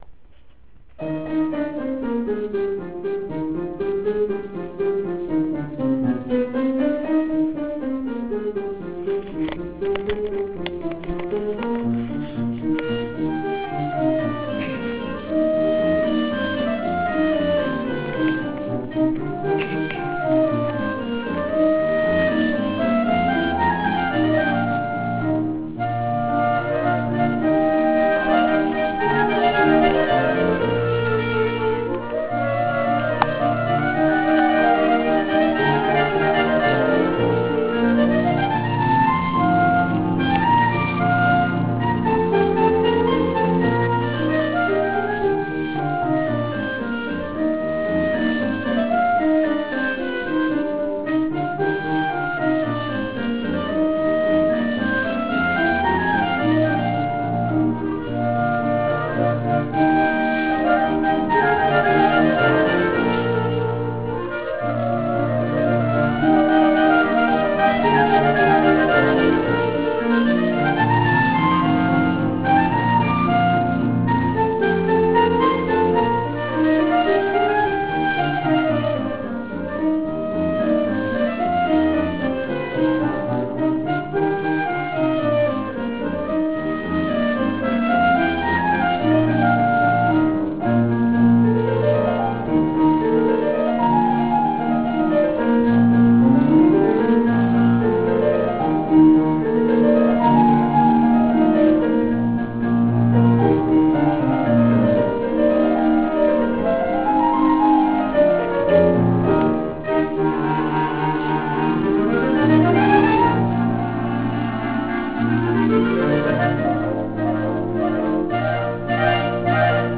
15. komorní koncert na radnici v Modřicích
Dechové trio TIBIA
Jako novinku jsme pro čtenáře MIM připravili amatérskou zvukovou nahrávku ukázky z koncertu:
F. V. Kramář: Koncert Es-dur op. 91 (3. věta Alla Polacca) pro dva klarinety a orchestr,